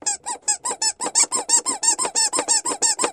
Baby Toy Squeeze Toy, Several Squeezes